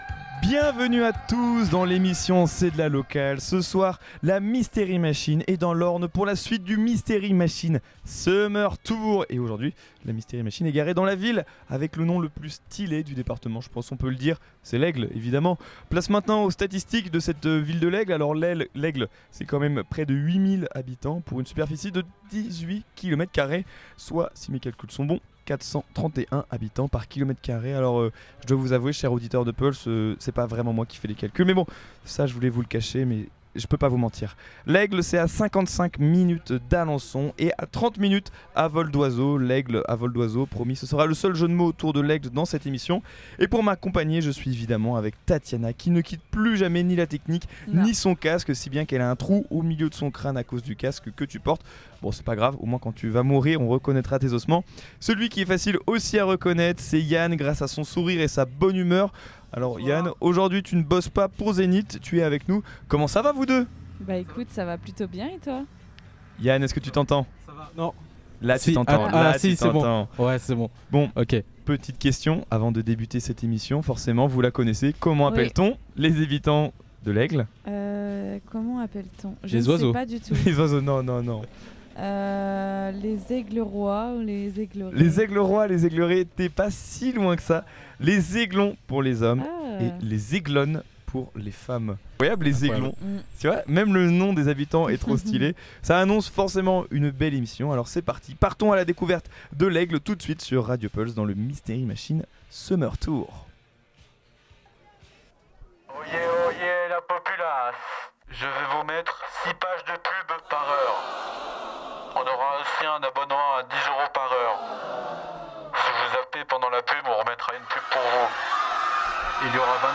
"C'est de la Locale" est une émission quotidienne diffusée en direct de 18 à 19h du lundi au vendredi.
On vous présente des artistes Normand avec la rubrique "Le local de l'étape" co-produit par le collectif de radios musiques actuelles Normand "La Musicale" Cet été, nous organisons la Mystery Machine Summer Tour, c’est-à-dire une émission en direct de 18h à 19h, diffusée depuis différentes villes et villages de l’Orne et de la Normandie. Dans cette émission, l’objectif est de présenter la ville, les activités à y faire, son histoire, les acteurs de la vie culturelle et associative, ainsi que les différents événements prévus cet été.